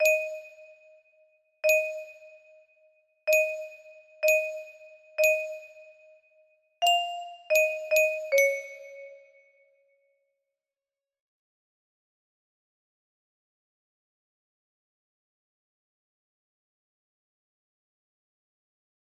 Unknown Artist - Untitled music box melody